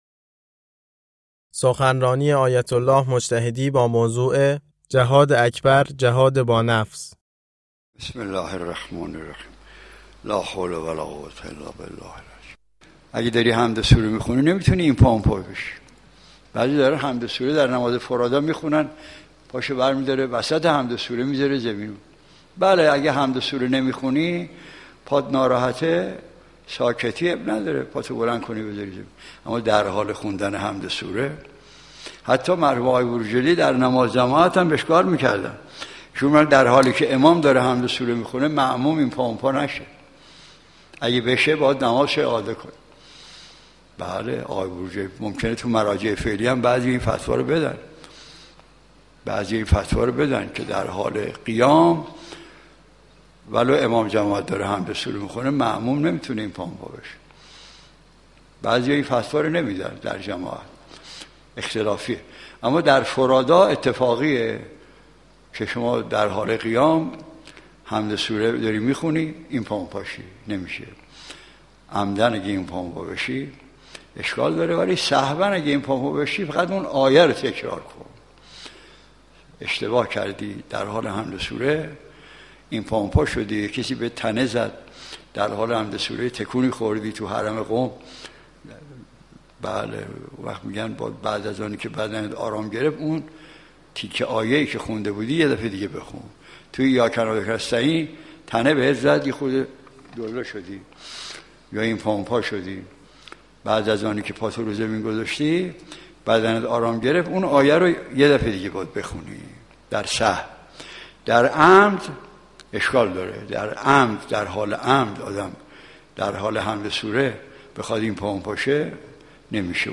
سخنرانی آیت الله مجتهدی تهرانی با موضوع جهاد اکبر، جهاد با نفس